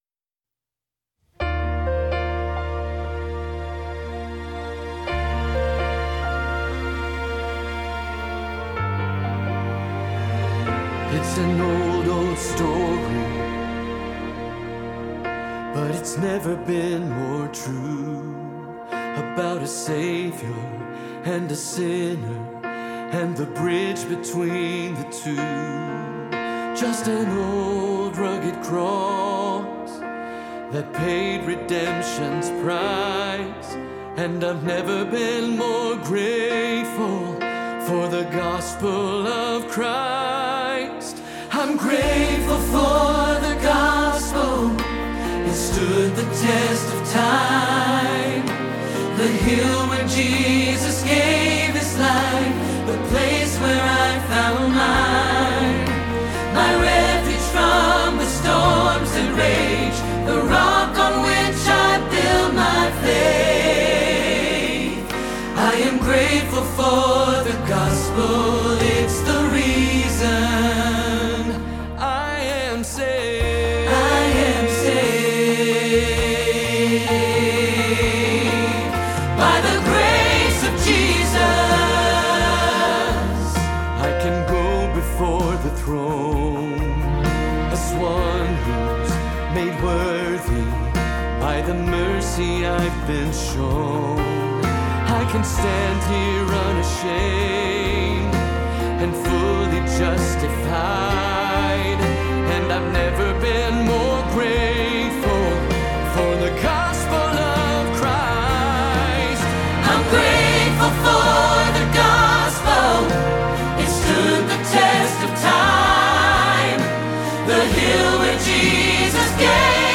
Grateful for the Gospel – Soprano – Hilltop Choir
05-Grateful-for-the-Gospel-Soprano-Rehearsal-Track.mp3